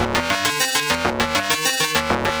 Index of /musicradar/8-bit-bonanza-samples/FM Arp Loops
CS_FMArp C_100-C.wav